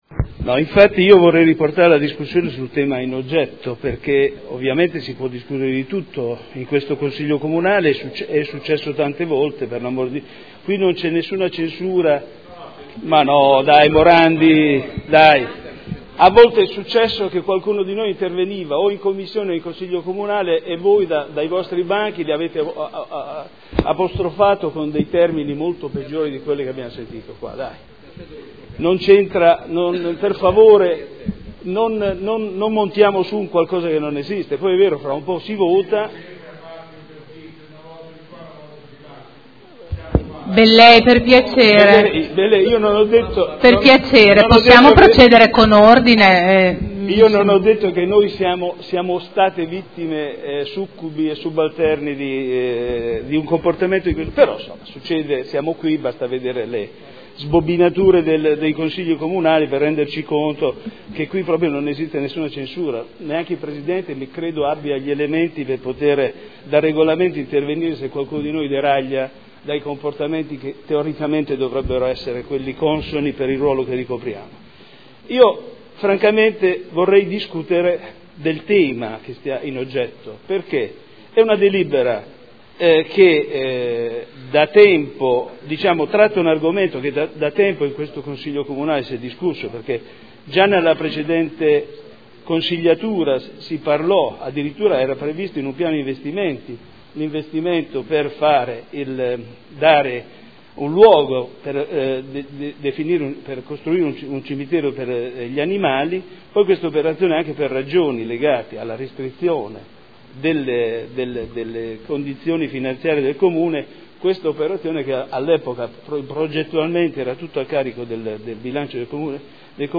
Michele Andreana — Sito Audio Consiglio Comunale